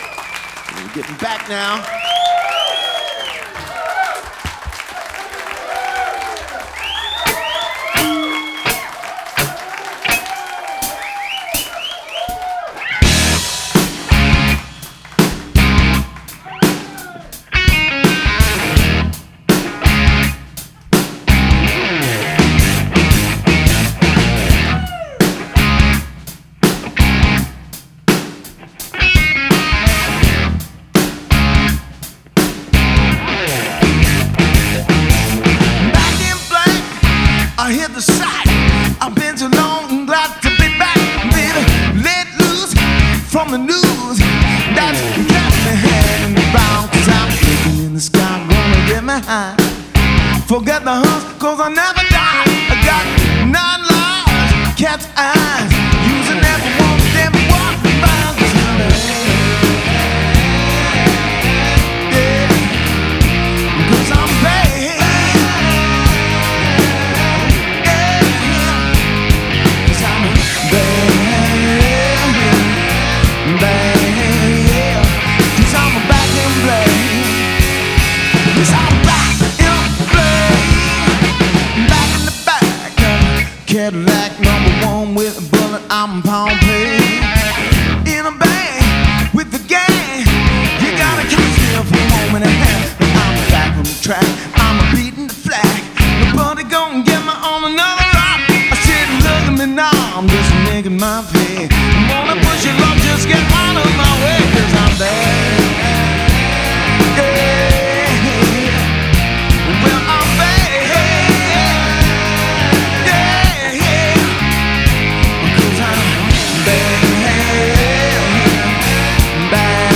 straight ahead classic 70’s Rock